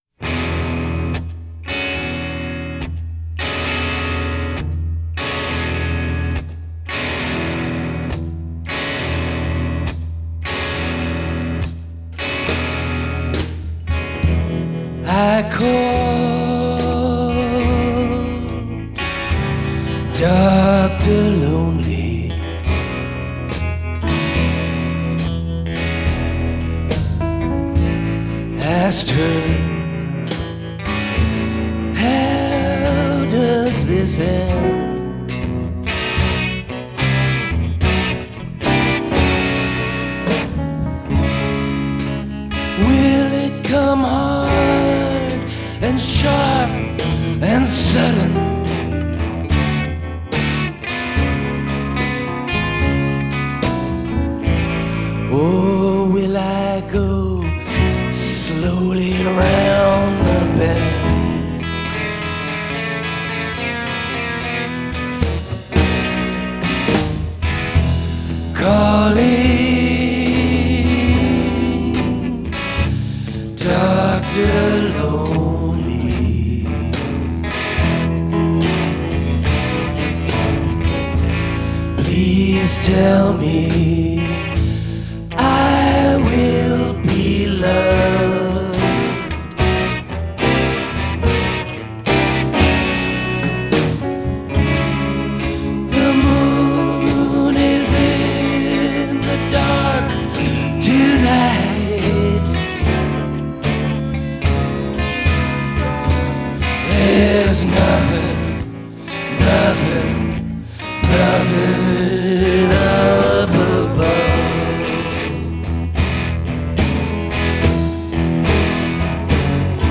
vocals/guitar
piano/vocals
bass/vocals
drums